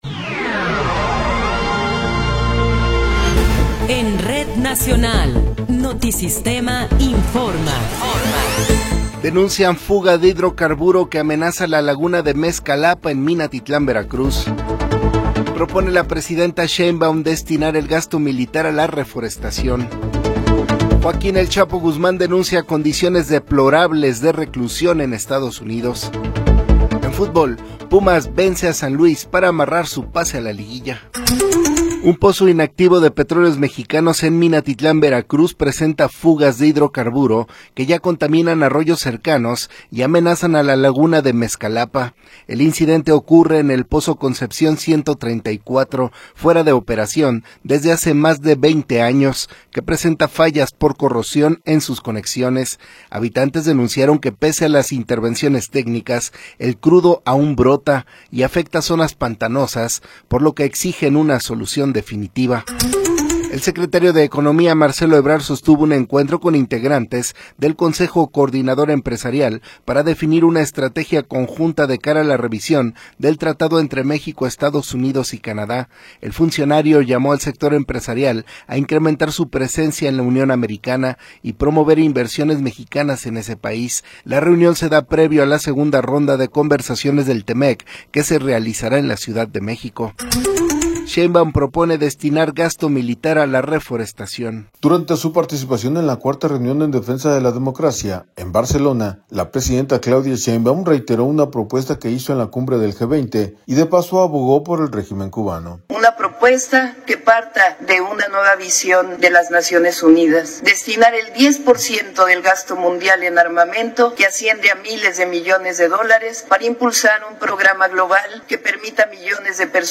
Noticiero 8 hrs. – 18 de Abril de 2026